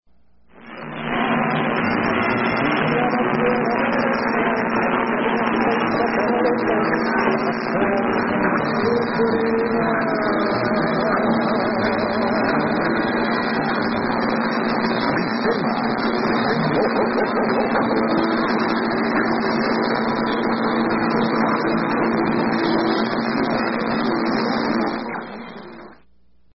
Sistema de Oro anmt, nothing more